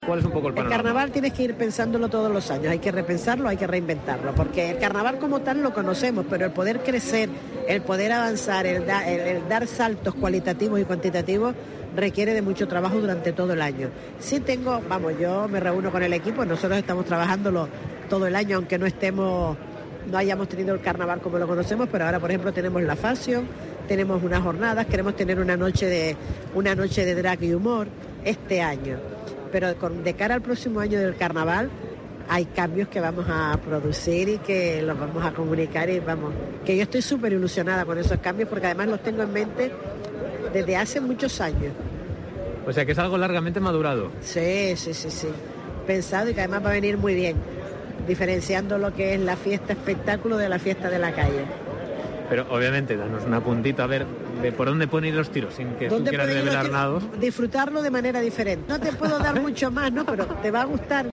Inmaculada Medina, concejala de Carnaval de Las Palmas de Gran Canaria
Inmaculada Medina, concejala de Carnaval del Ayuntamiento de Las Palmas de Gran Canaria, ha asegurado en una entrevista en La Mañana de COPE Gran Canaria que la fiesta de la máscara sufrirá modificaciones con respecto a la última vivida en 2020.